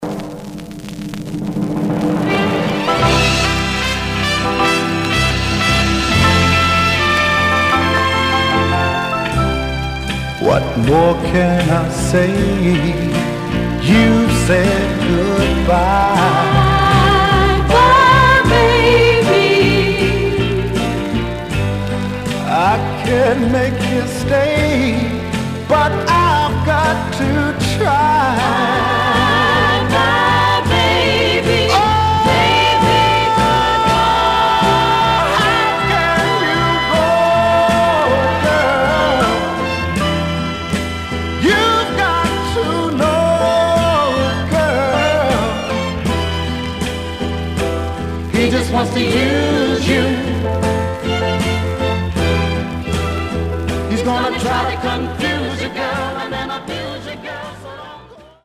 Some surface noise/wear Stereo/mono Mono
Soul